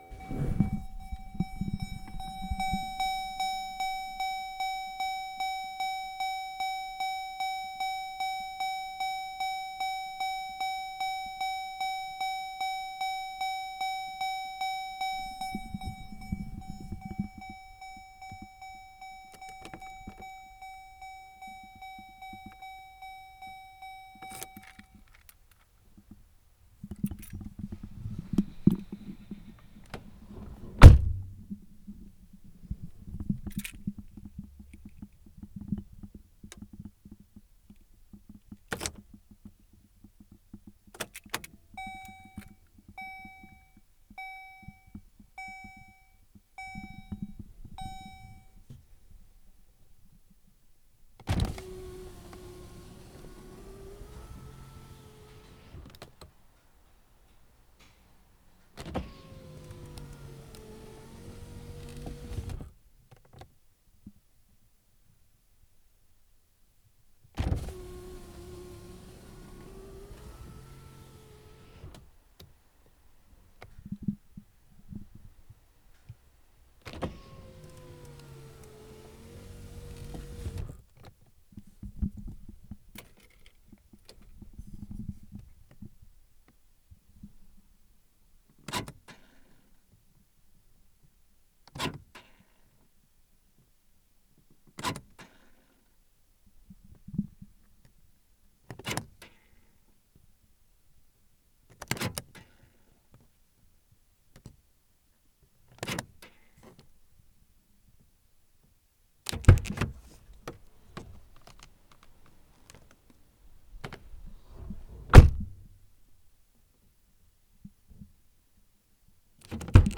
Various Int. Car Noises
car close dink door open window sound effect free sound royalty free Nature